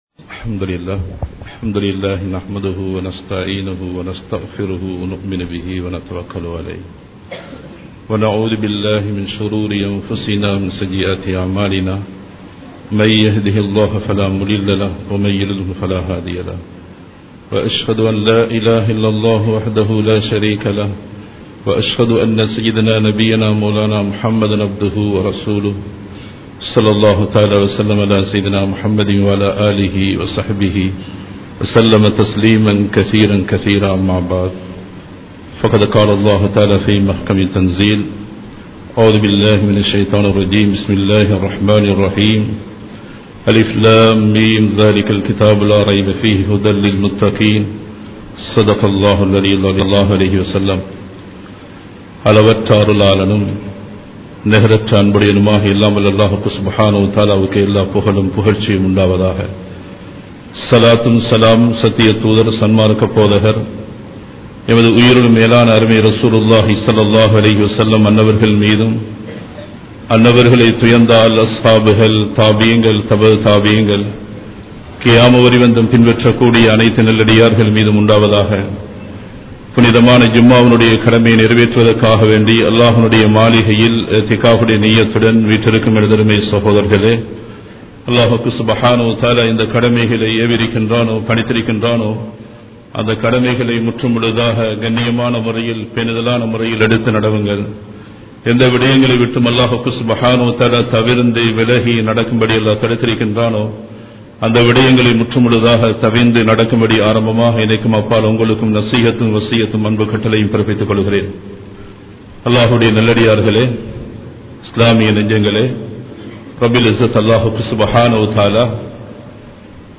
நிதானமாக வாழ்வோம் | Audio Bayans | All Ceylon Muslim Youth Community | Addalaichenai
Colombo 03, Kollupitty Jumua Masjith